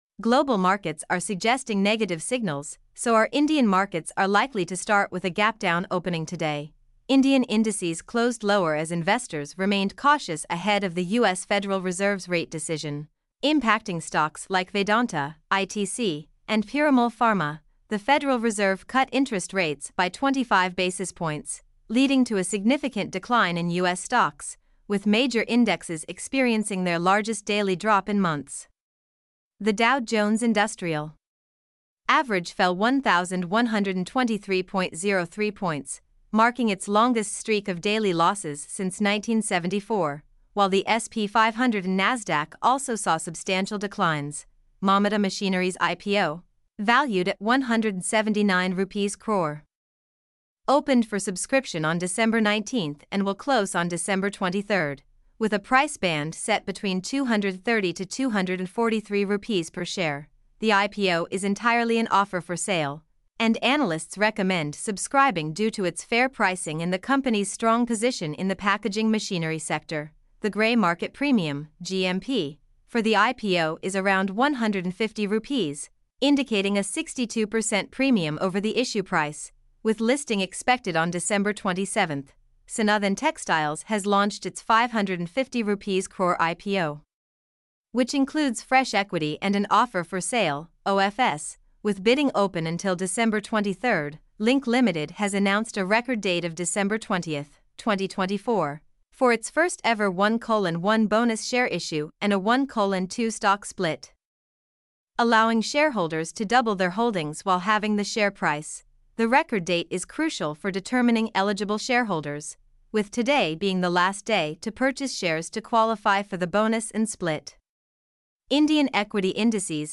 mp3-output-ttsfreedotcom.mp3